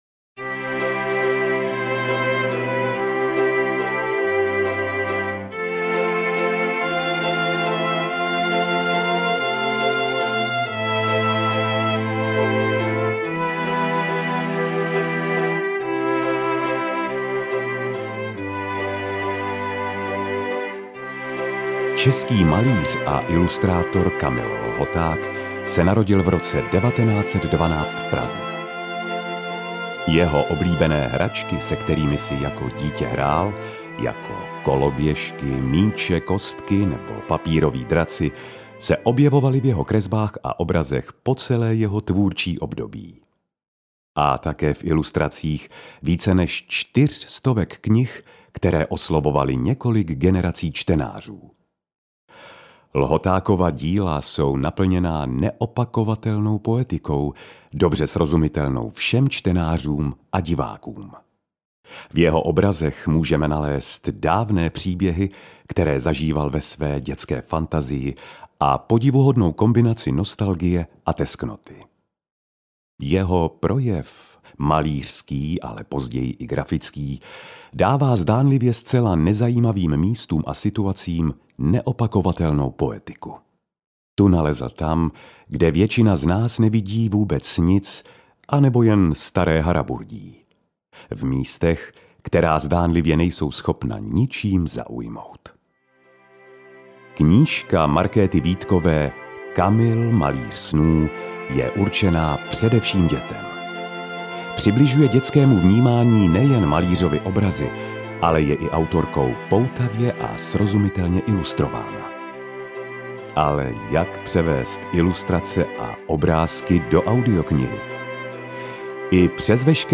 Ukázka z knihy
• InterpretPetr Stach